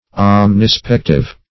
Meaning of omnispective. omnispective synonyms, pronunciation, spelling and more from Free Dictionary.
Search Result for " omnispective" : The Collaborative International Dictionary of English v.0.48: Omnispective \Om`ni*spec"tive\, a. [Omni- + L. spectus, p. p. of specere, spicere, to view.]